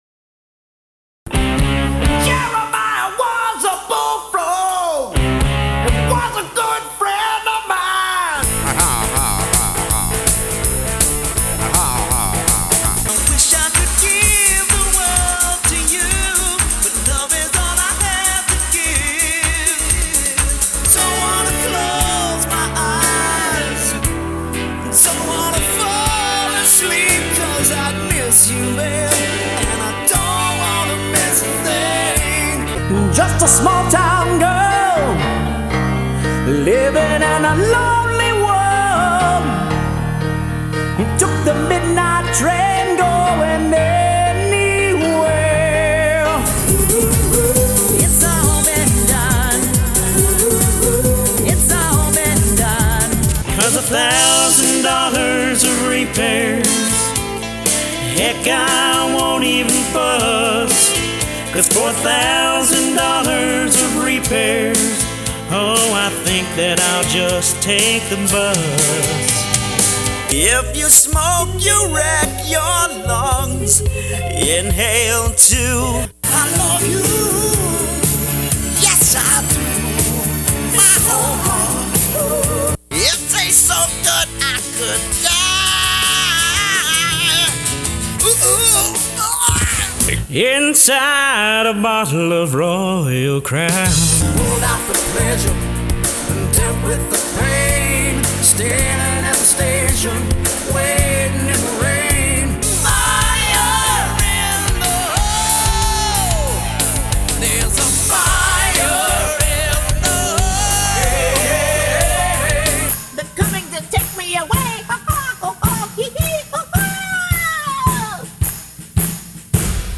Singer of many styles
Singing
Singing Demo Variety Mashup